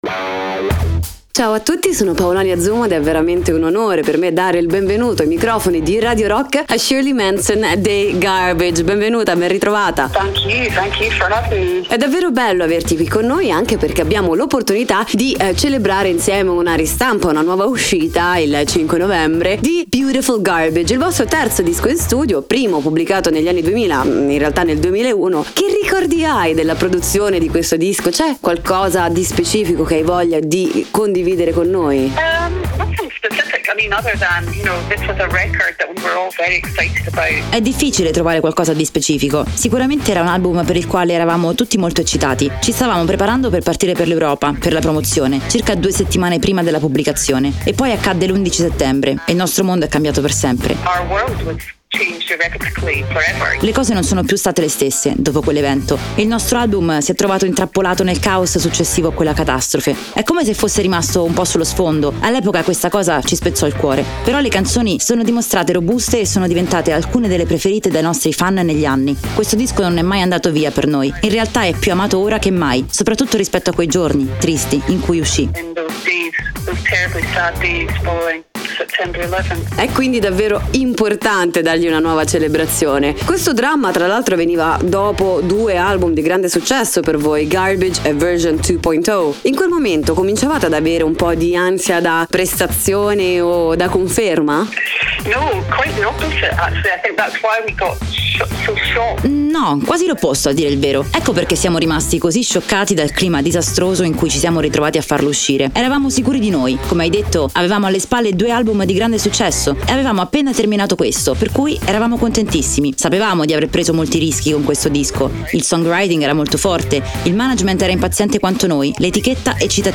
Interviste: Garbage (05-11-21)